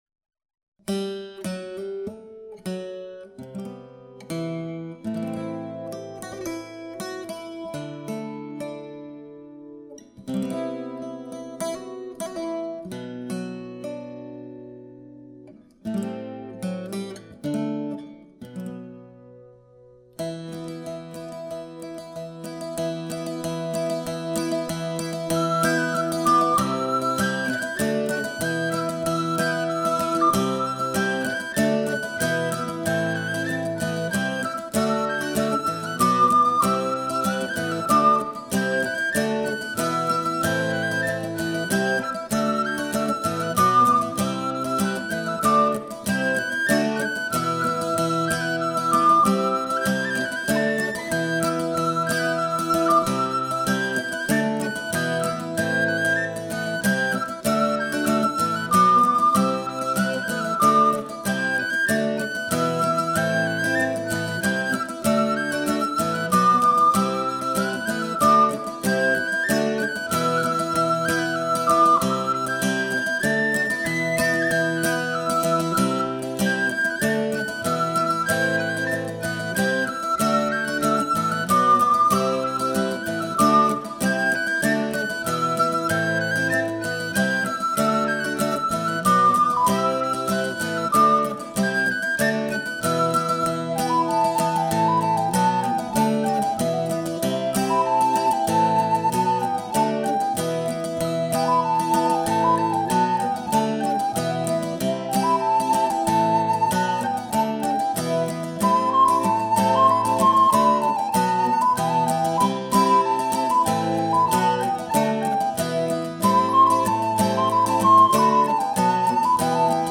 Erledanz - Folk von der deutsch-französischen Grenze
Erledanz spielt auf zum Bal Folk.